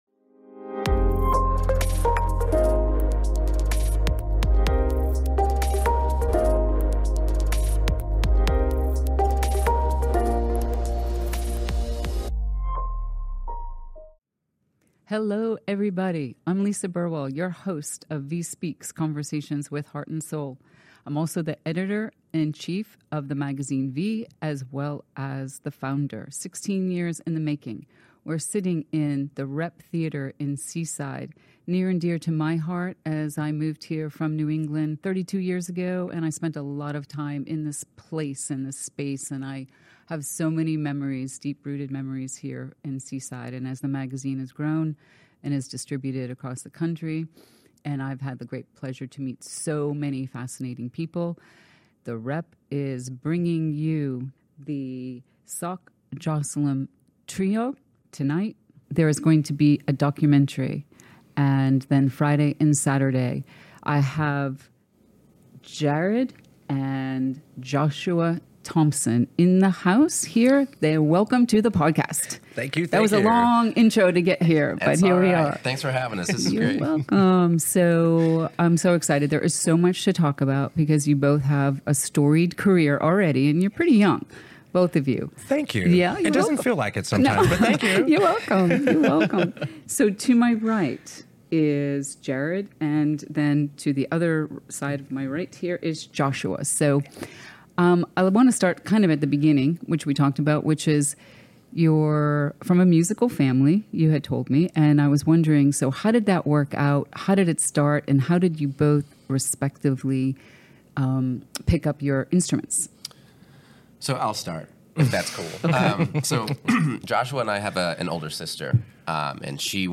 75: "A Journey into Sound" - A Conversation